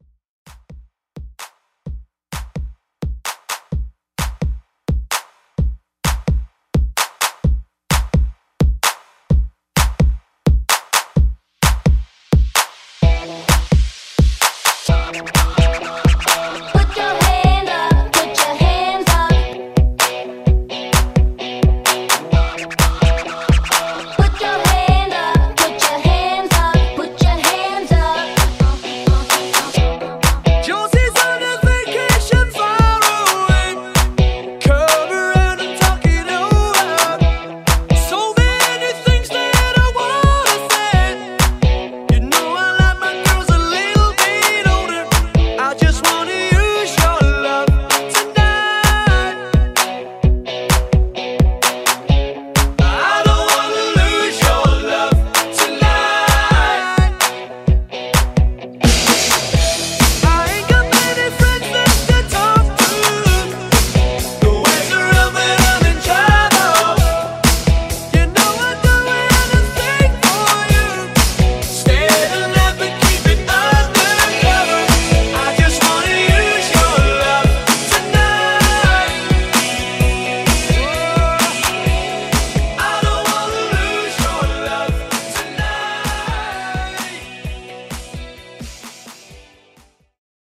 80s Rock Redrum